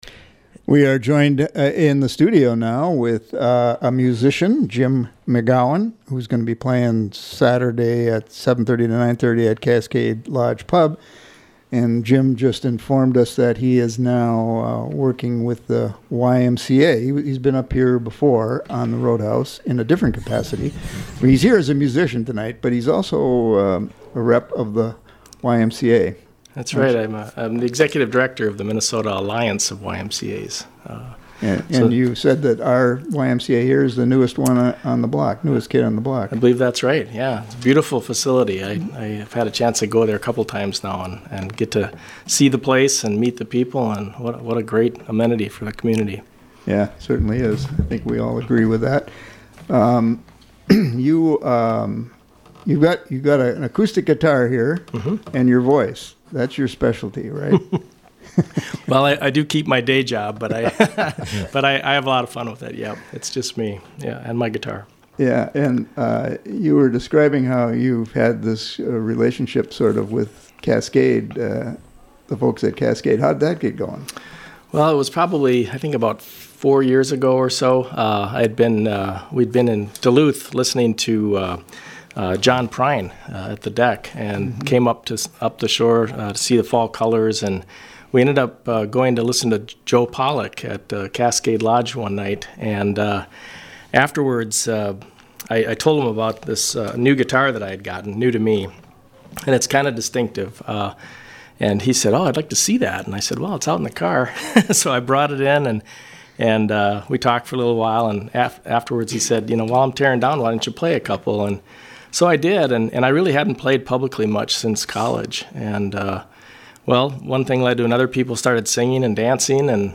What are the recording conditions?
Program: Live Music Archive The Roadhouse